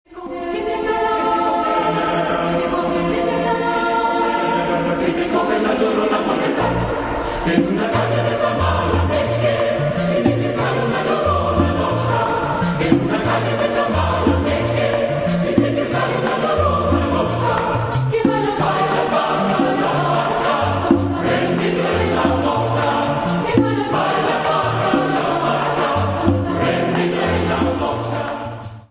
Genre-Style-Form: Secular ; Folk music ; porro
Mood of the piece: joyous
Type of Choir: SATB  (4 mixed voices )